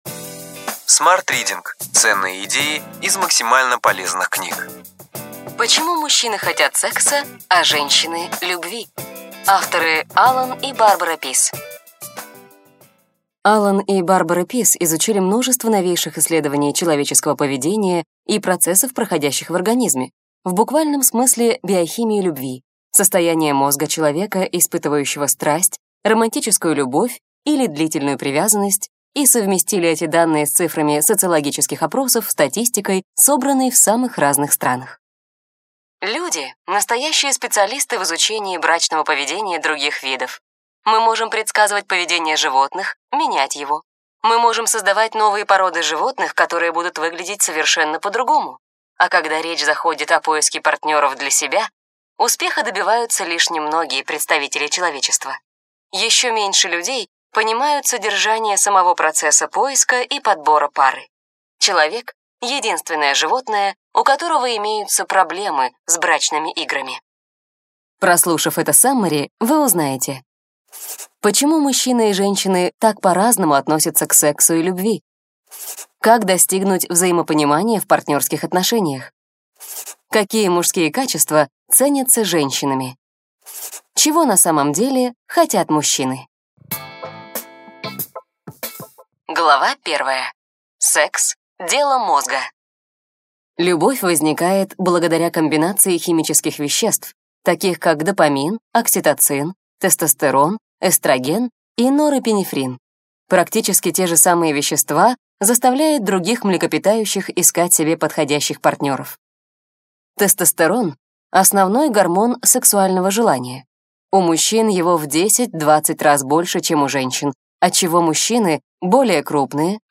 Аудиокнига Ключевые идеи книги: Почему мужчины хотят секса, а женщины любви. Аллан Пиз, Барбара Пиз | Библиотека аудиокниг